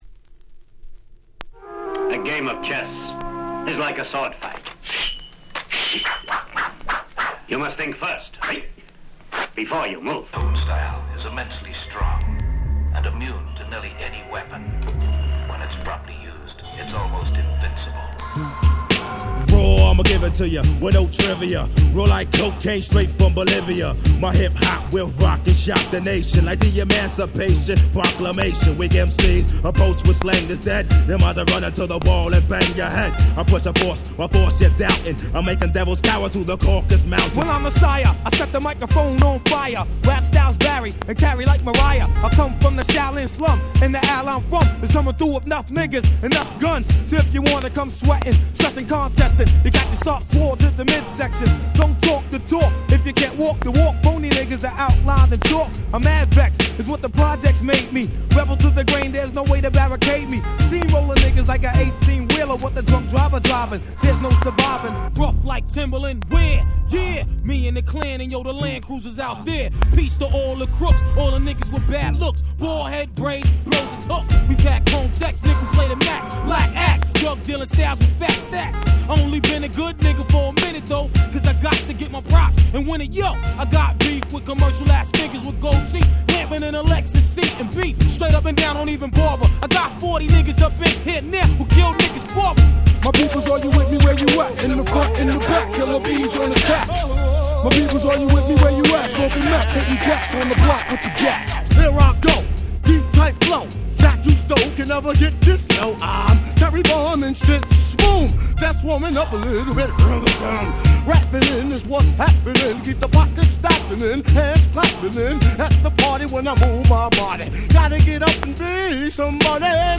93' Super Hip Hop Classics !!